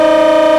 5 chime horn 4a.ogg